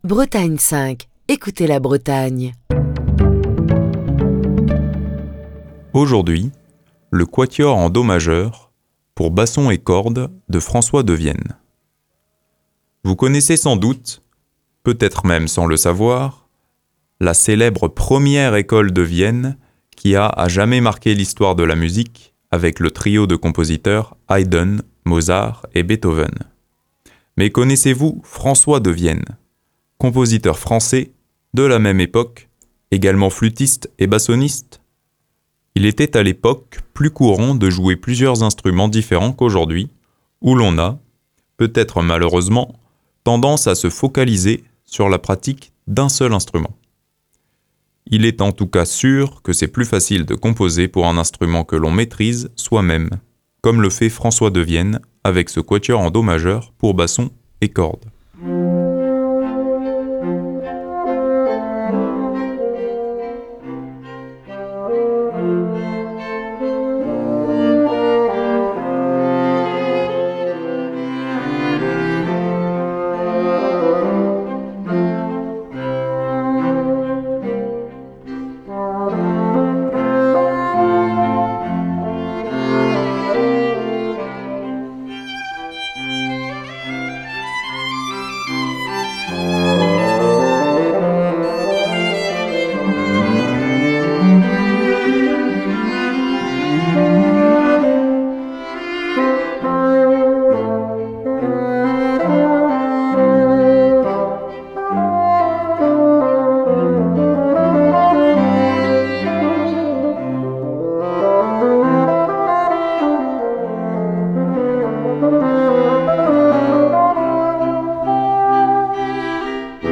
Fil d'Ariane Accueil Les podcasts François Devienne - Quatuor en Do majeur pour basson et cordes François Devienne - Quatuor en Do majeur pour basson et cordes Émission du 29 novembre 2023.
deuxième mouvement plein de charme
joueur, humoristique, goguenard